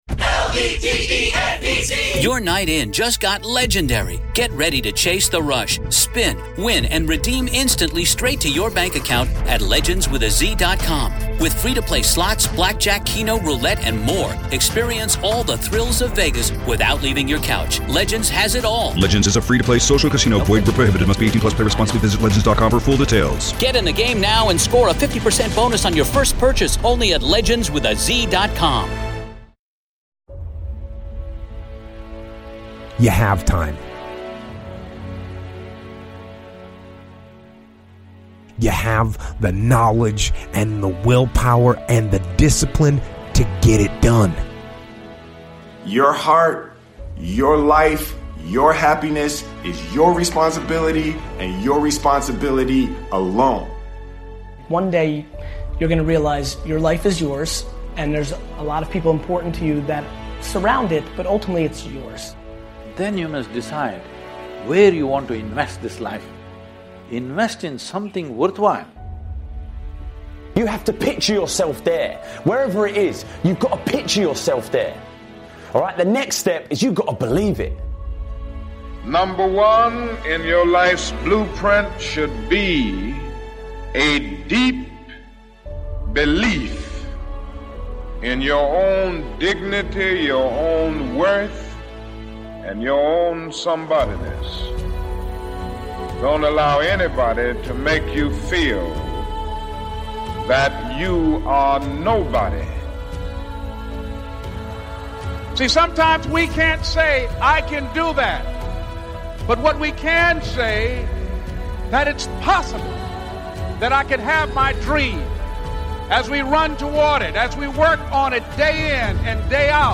Speaker: Jocko Willink